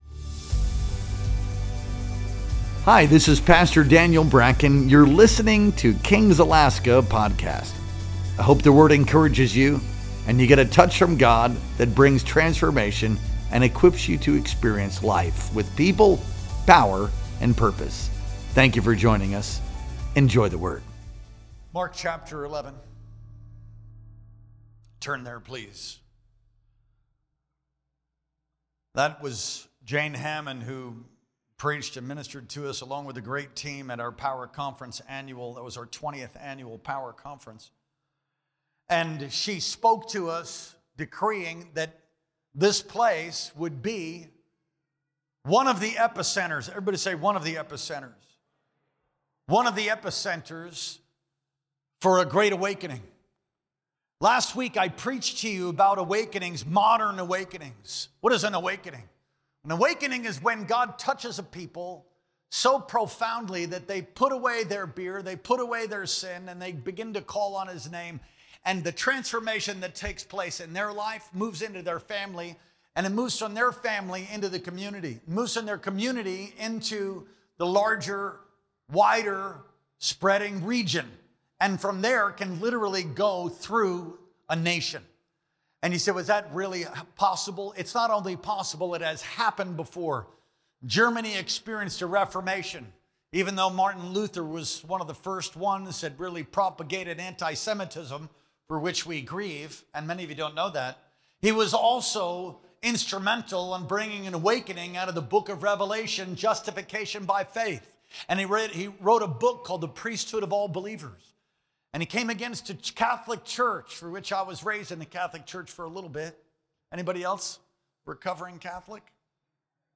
Our Sunday Worship Experience streamed live on March 2nd, 2025.